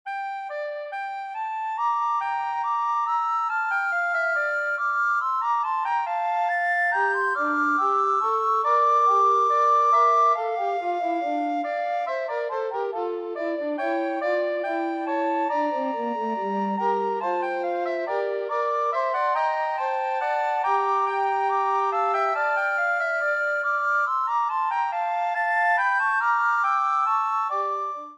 S A T B